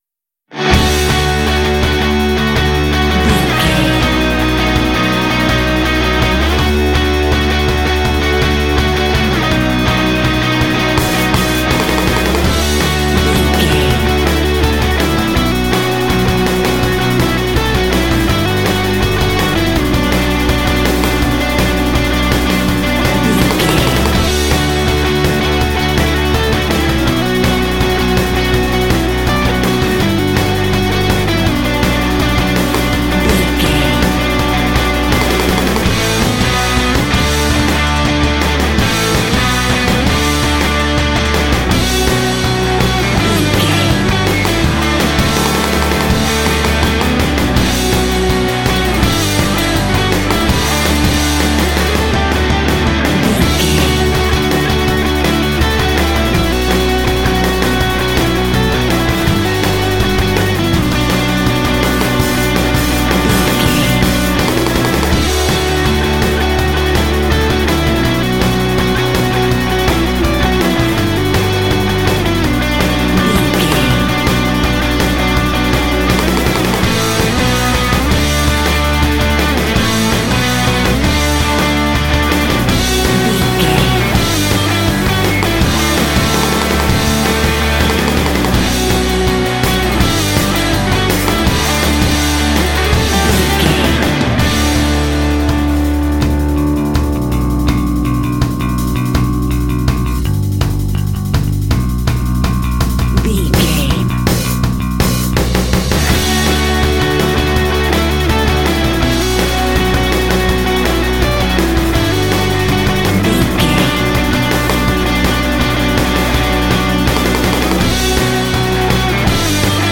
Ionian/Major
D
Fast
driving
energetic
lively
classic rock
alternative rock